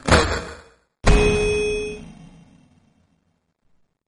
fnf_loss_sfx-pixel.mp3